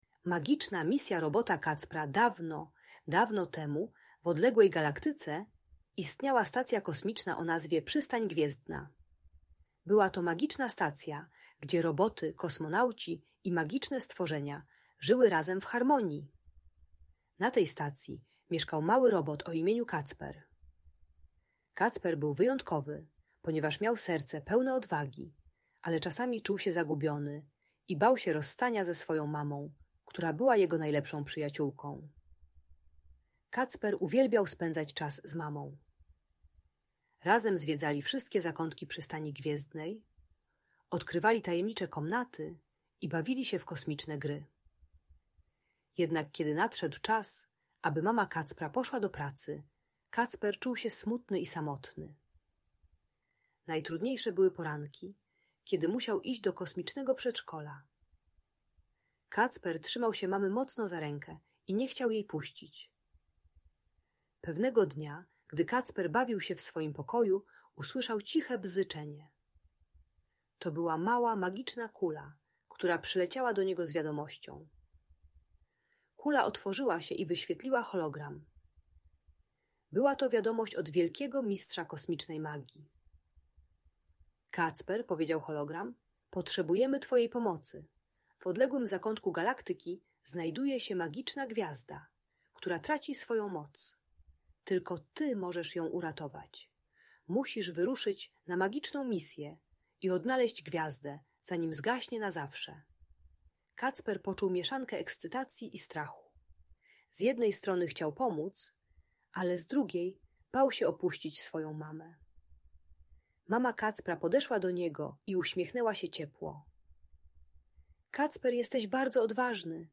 Magiczna Misja Robota Kacpra - Opowieść o Odwadze - Audiobajka